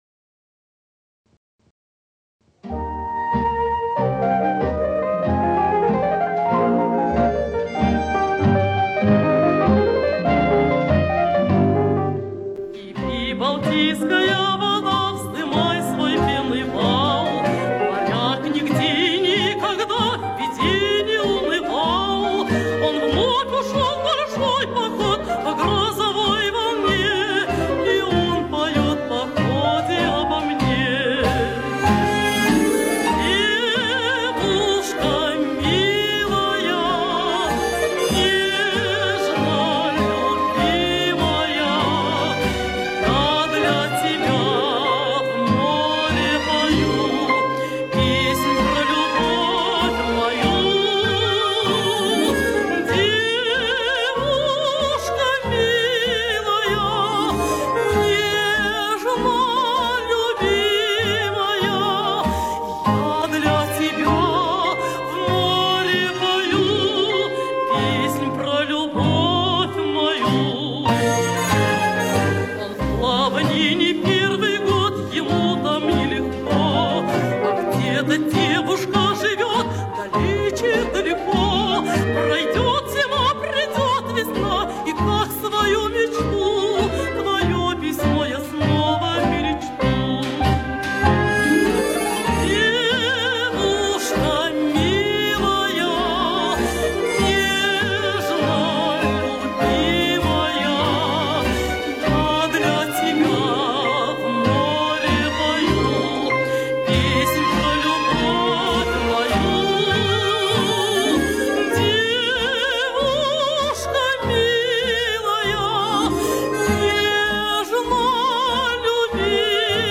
Из передачи по радио